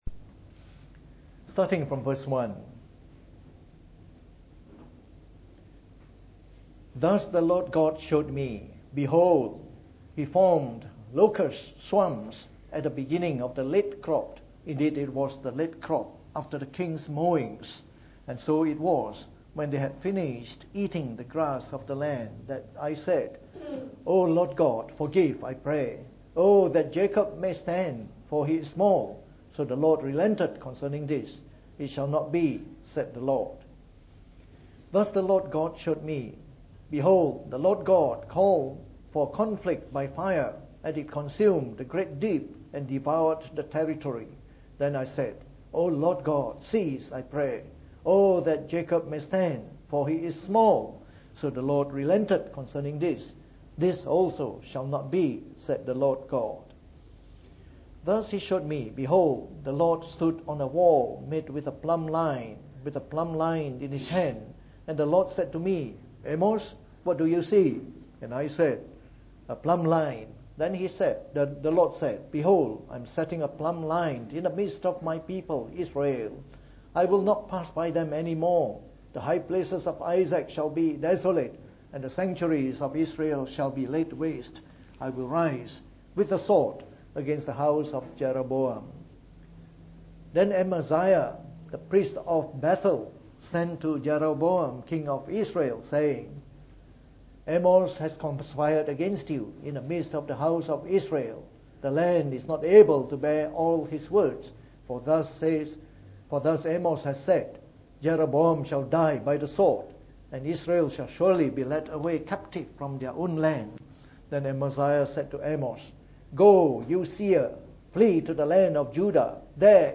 From our series on the Book of Amos delivered in the Morning Service.